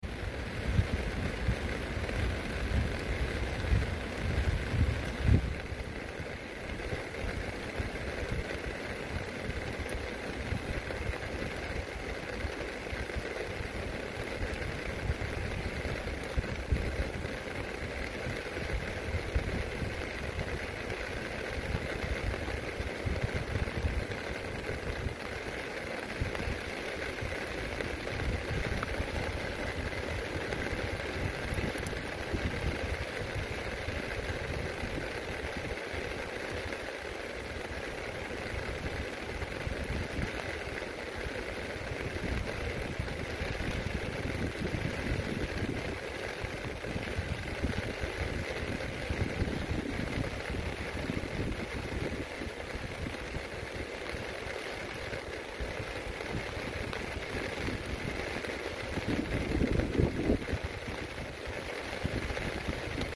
Rain sound in the river sound effects free download